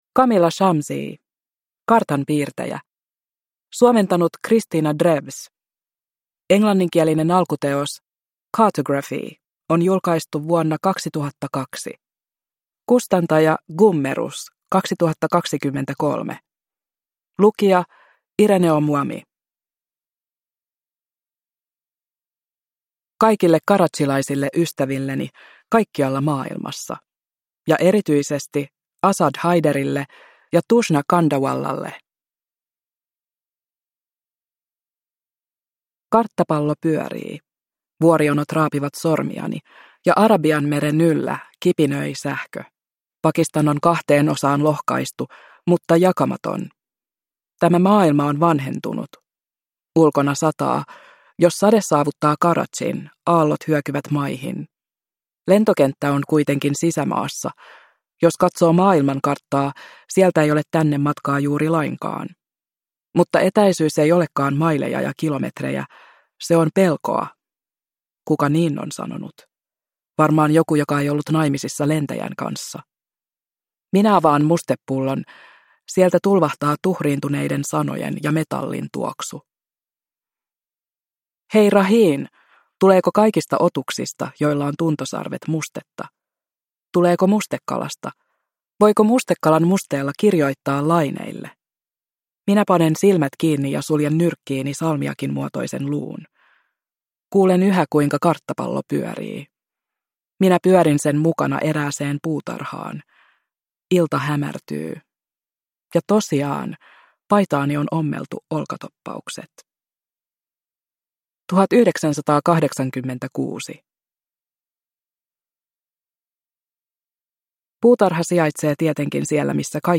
Kartanpiirtäjä – Ljudbok – Laddas ner